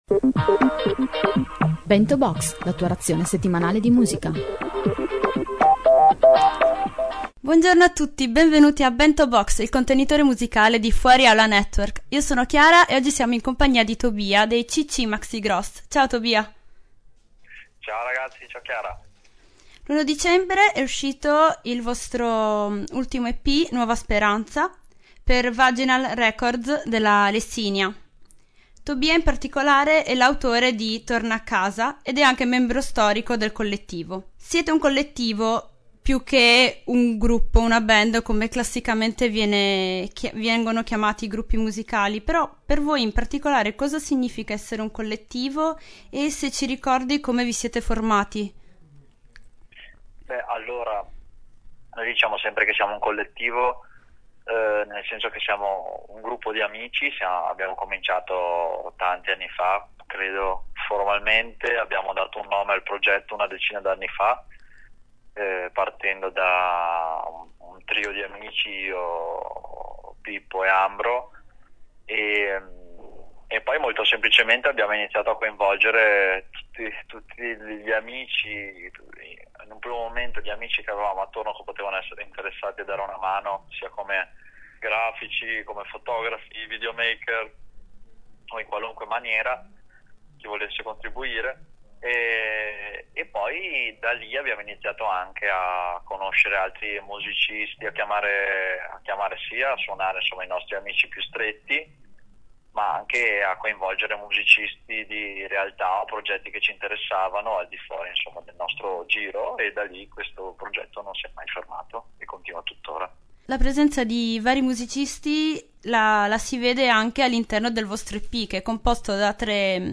Nell’intervista conosceremo la nascita di quest’ultimo lavoro e i loro progetti per il futuro.